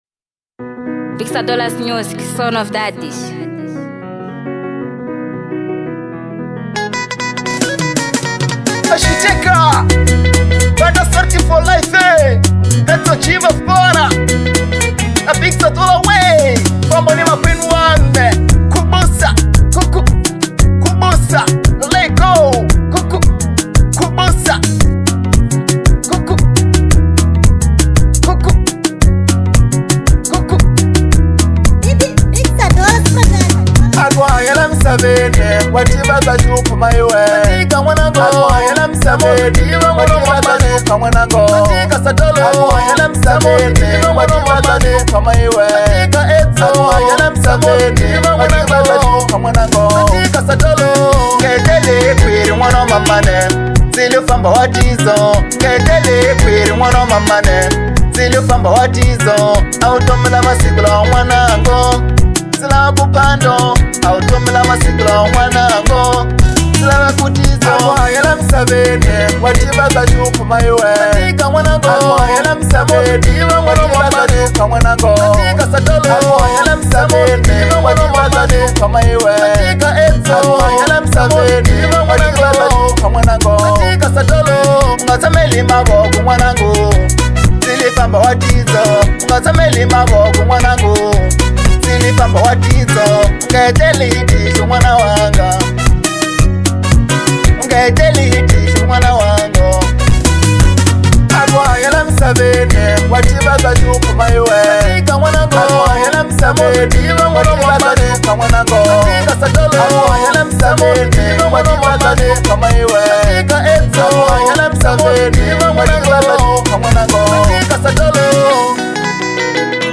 Genre : Marrabenta